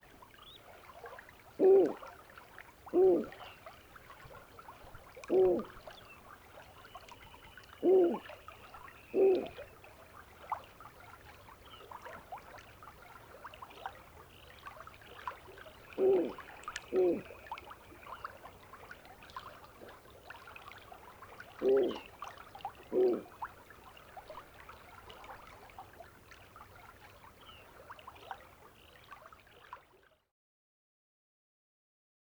Snowy Owl
He inflates his throat, then hoots while thrusting his head down and cocking his tail at right angles to the ground (CD3-47).
Hooting of a male.
47-Snowy-Owl-Hooting-Of-Male.wav